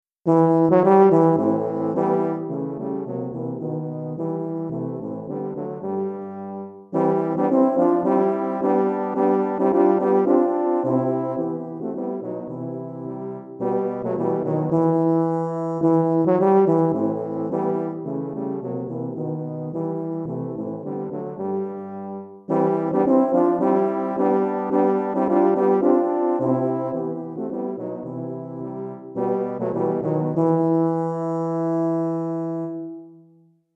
3 Tubas